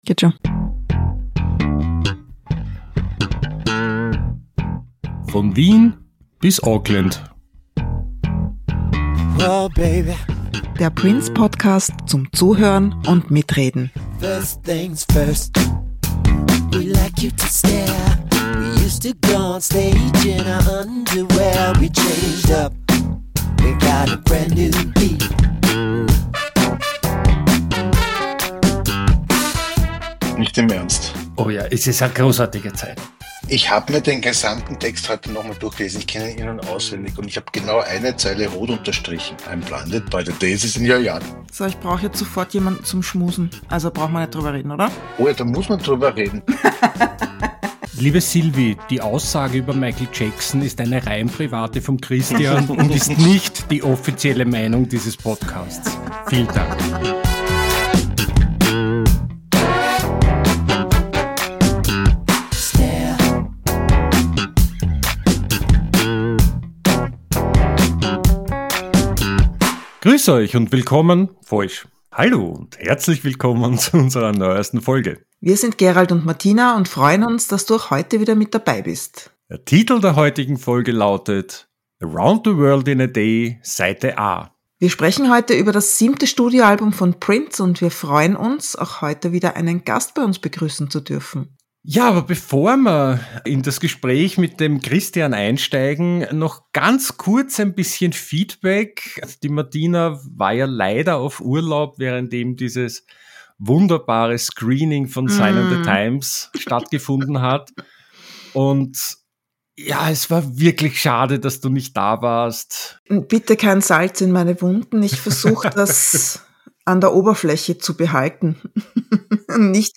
Wir plaudern – wie immer persönlich, nerdy und mit einer Prise Augenzwinkern – über Lieblingszeilen, versteckte Botschaften, Maxisingles, alte Erinnerungen … und wie sich unser Blick auf das Album im Lauf der Jahre verändert hat.